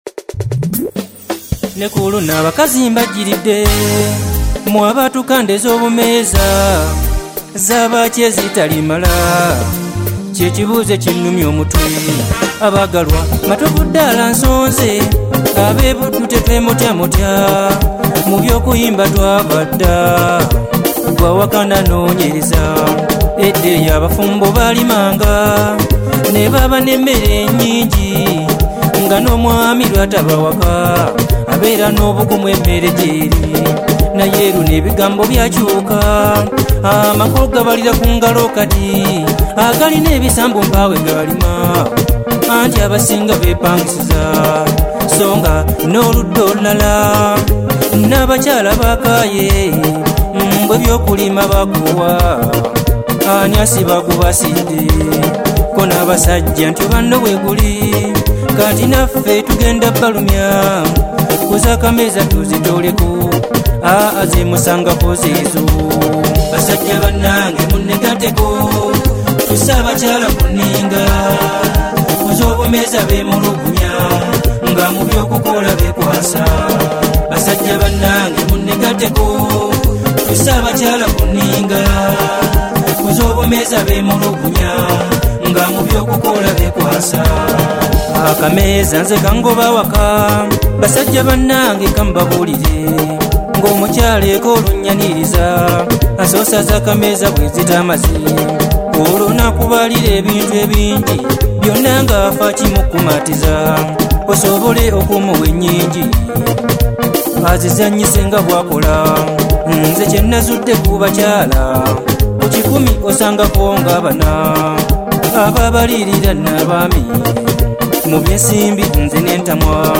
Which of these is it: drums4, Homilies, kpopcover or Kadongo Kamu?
Kadongo Kamu